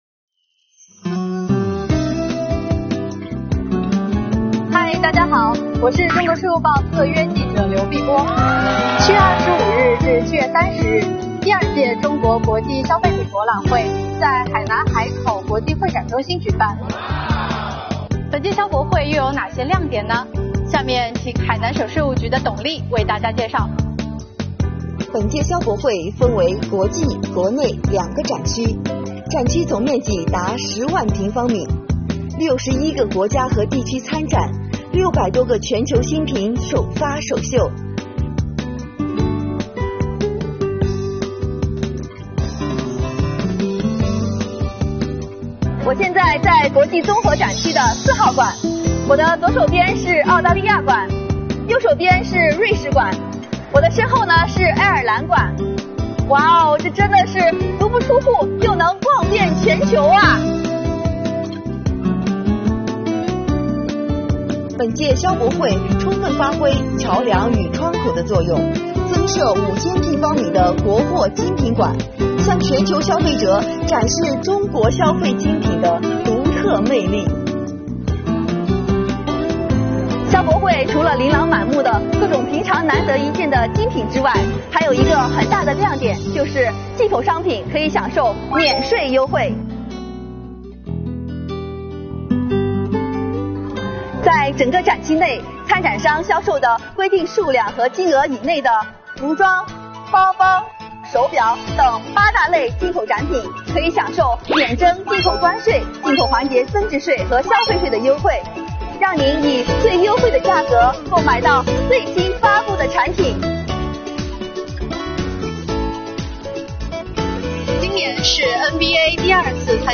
本届消博会还有哪些特点呢？请跟随中国税务报特约记者，一起来看看吧！